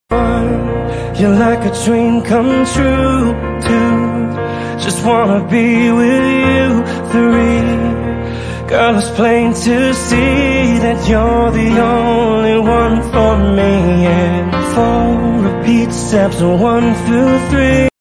piano acoustic cover